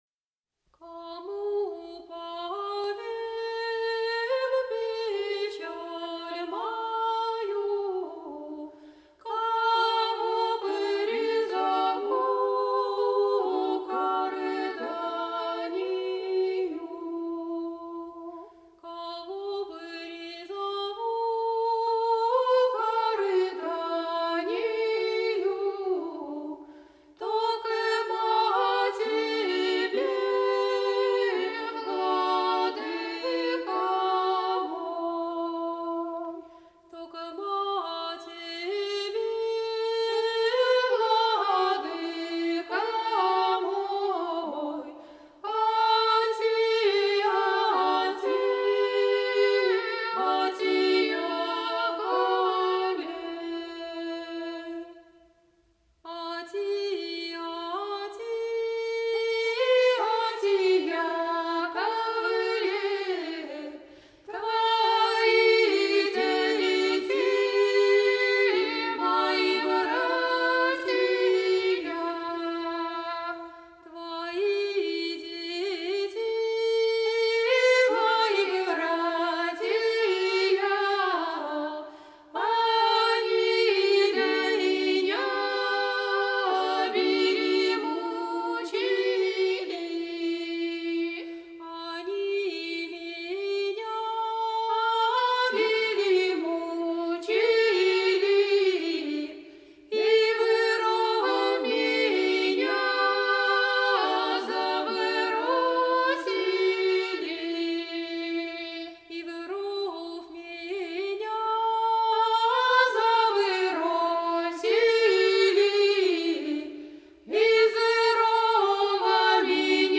01_Кому_повем_печаль_мою_-_духовный_стих.mp3